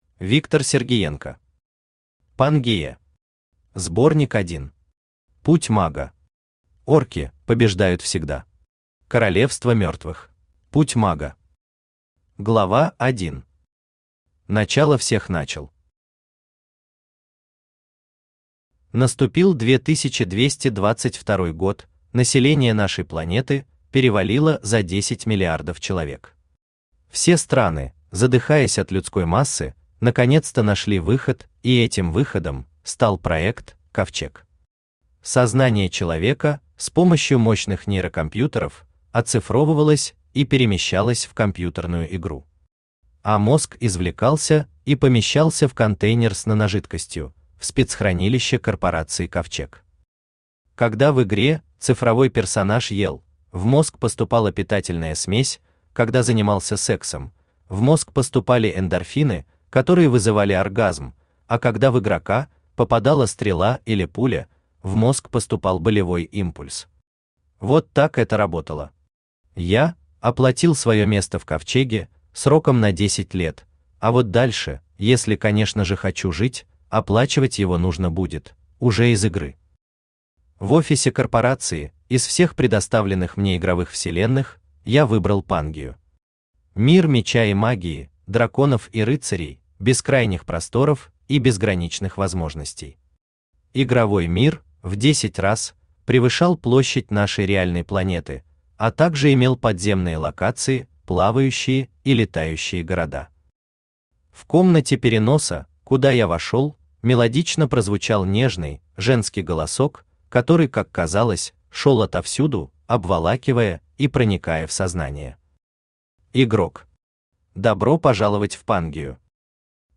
Королевство мертвых Автор Виктор Николаевич Сергиенко Читает аудиокнигу Авточтец ЛитРес.